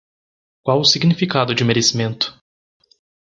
Uitgesproken als (IPA)
/me.ɾe.siˈmẽ.tu/